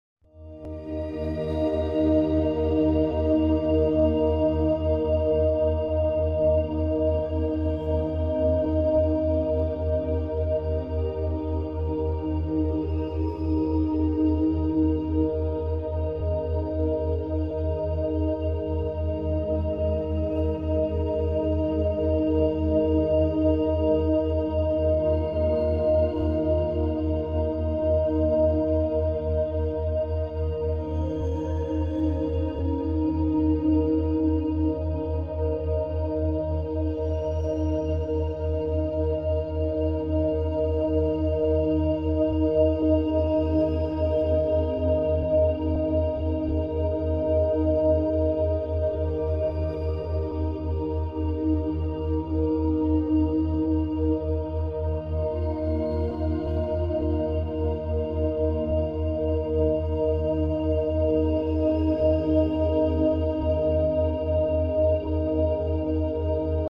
✨ Fréquence sacrée 777 Hz sound effects free download